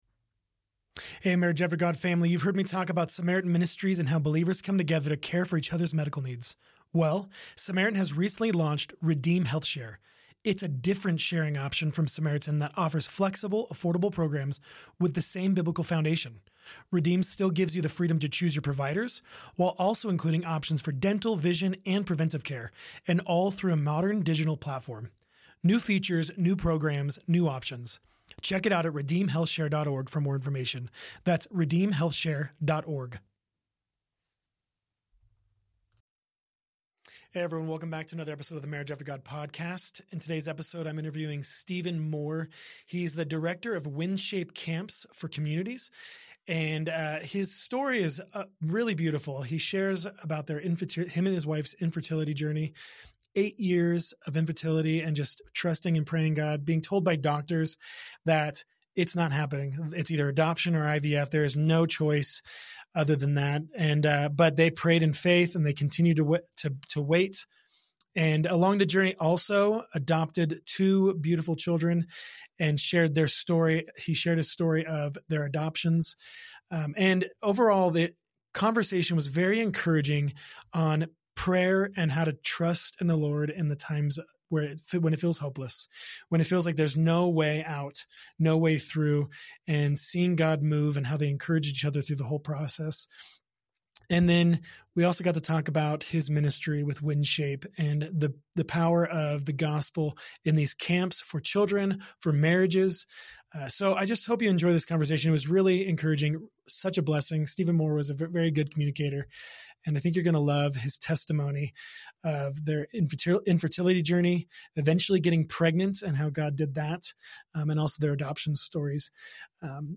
Relationship Encouragement, Uplifting interviews and Biblical Marriage And Parenting Advice